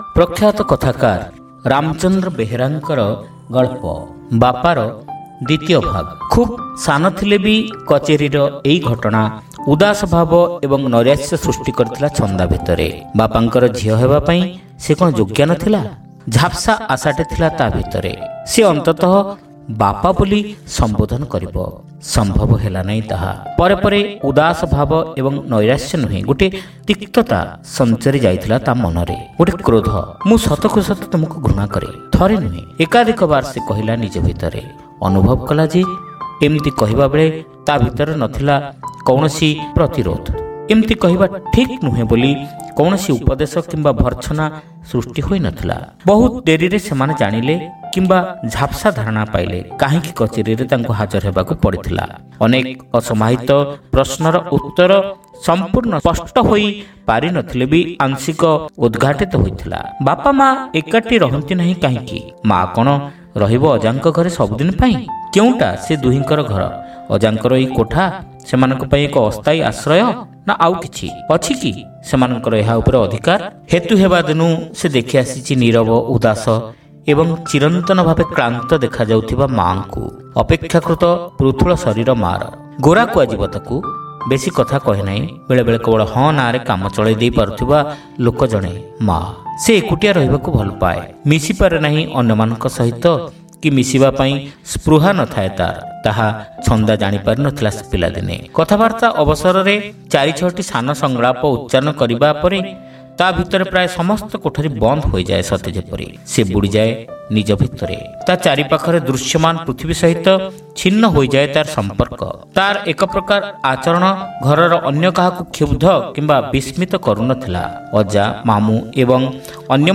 ଶ୍ରାବ୍ୟ ଗଳ୍ପ : ବାପା (ଦ୍ୱିତୀୟ ଭାଗ)